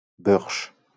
Bœrsch (French pronunciation: [bœʁʃ]